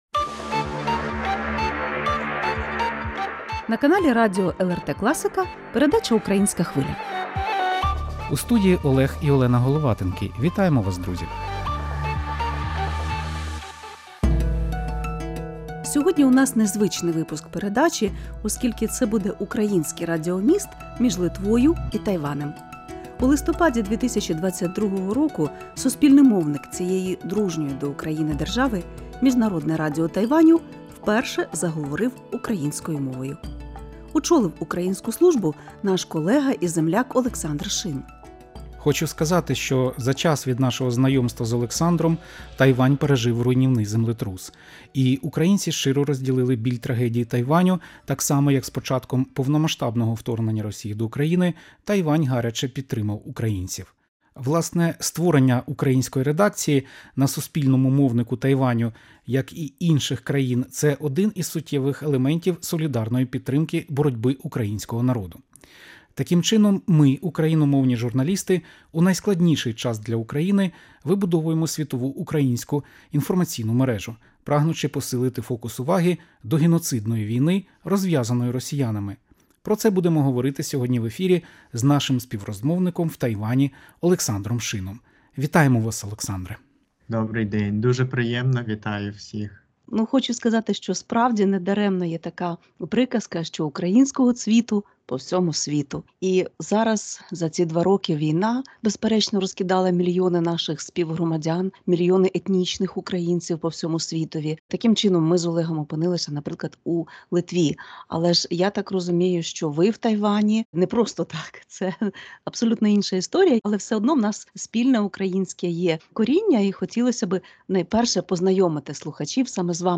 Пропонуємо Вашій увазі український радіоміст між Литвою і Тайванем.